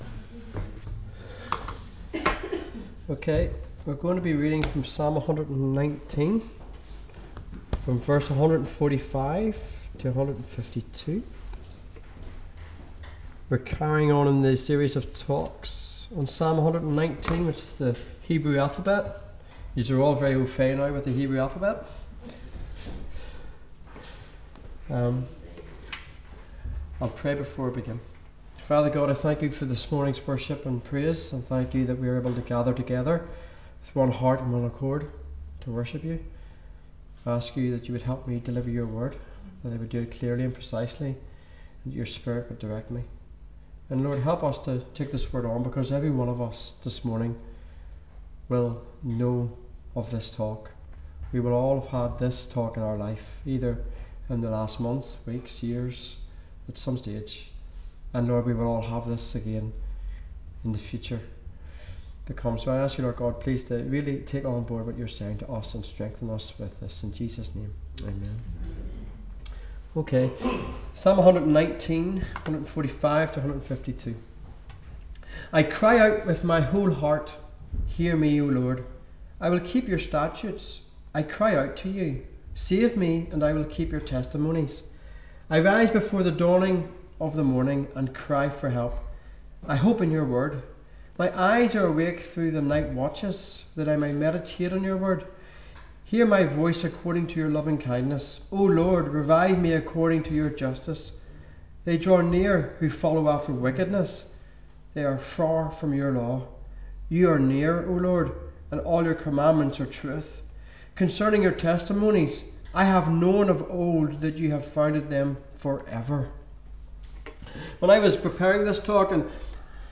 speaks from Psalm 119v145-152 on crying out to God and prevailing prayer.